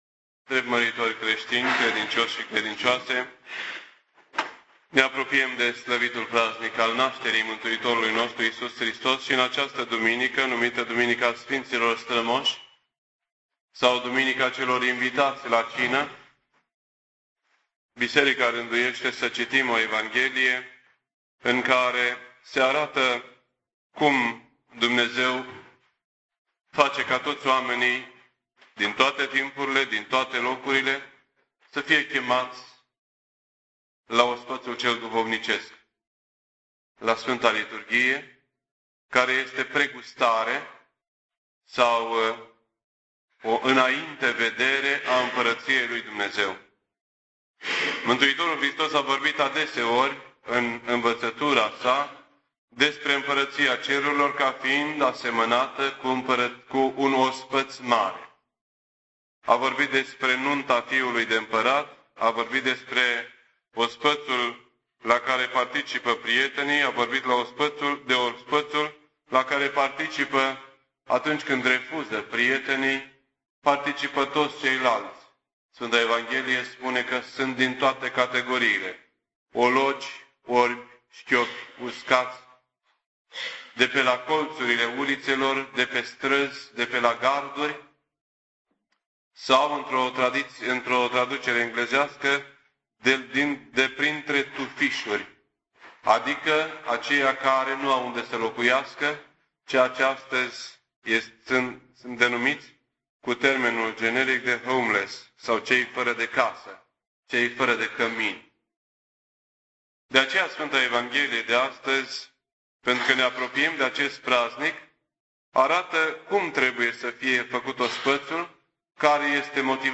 This entry was posted on Sunday, December 16th, 2007 at 9:59 AM and is filed under Predici ortodoxe in format audio.